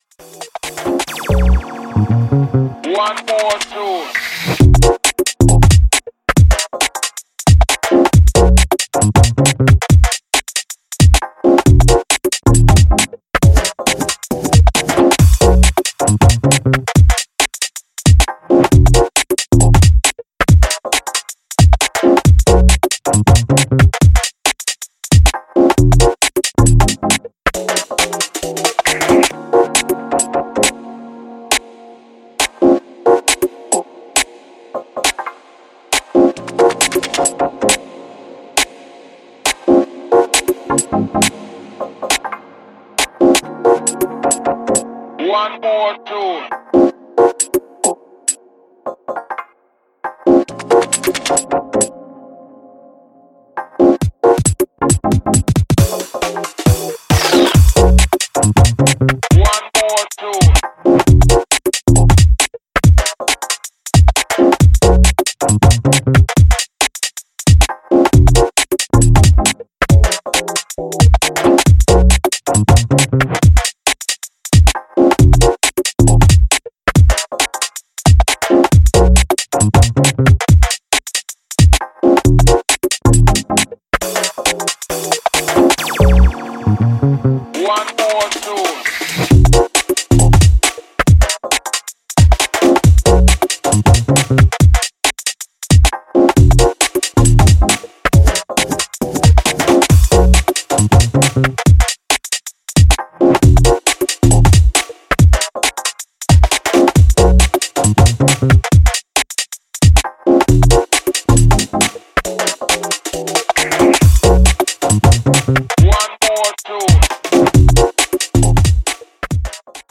Ukg